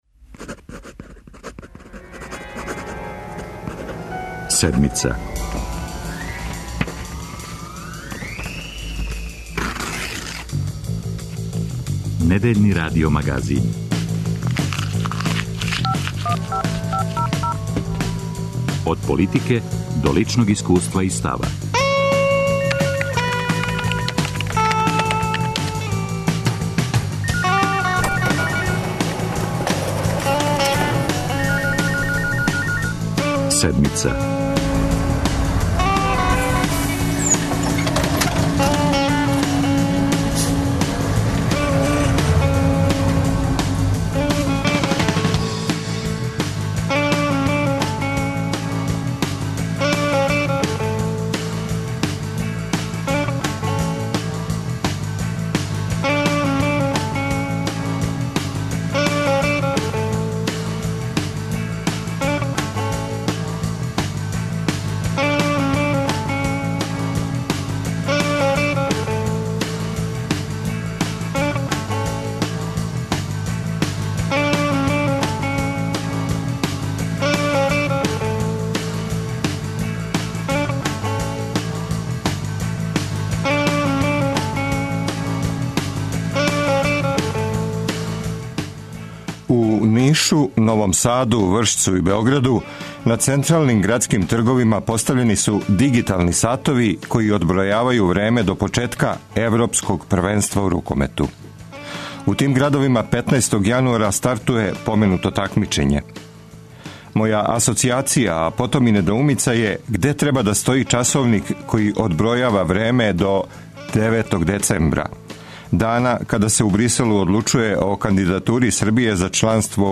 Више од стотину тешких срчаних болесника из Србије биће оперисано у Швајцарској. За Седмицу у овонедељном издању говоре пацијенти Универзитетске болнице у Женеви.